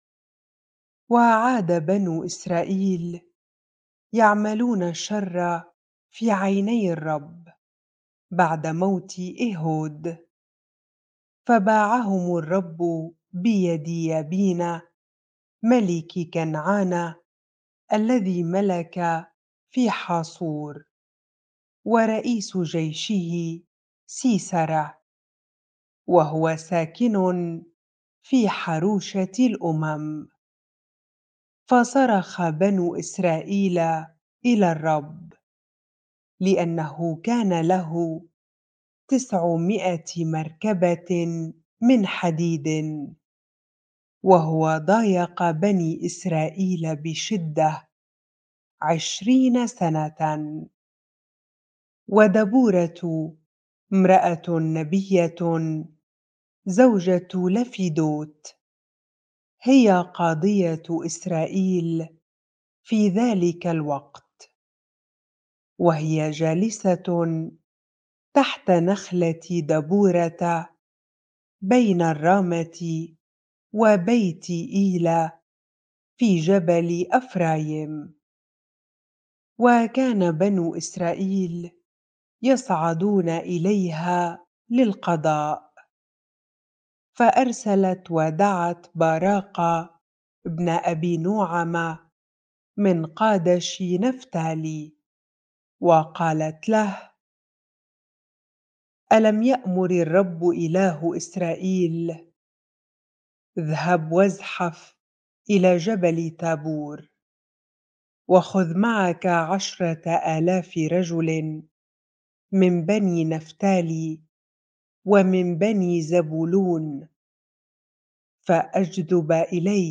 bible-reading-Judges 4 ar